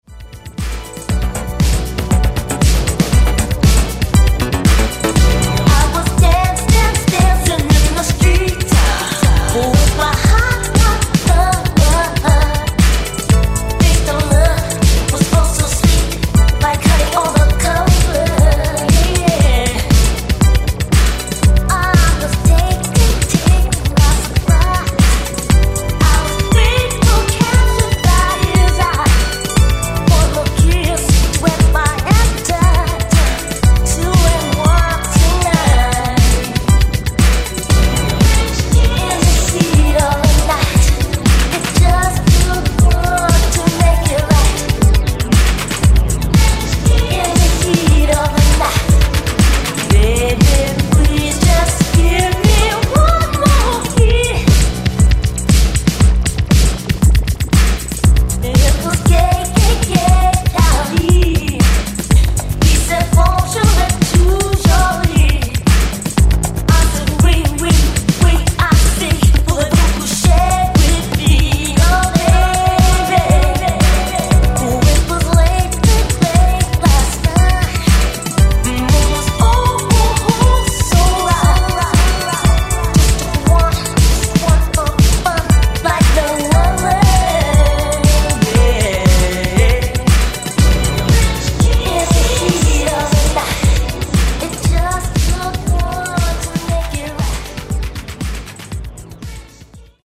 Genres: 70's , RE-DRUM
Clean BPM: 139 Time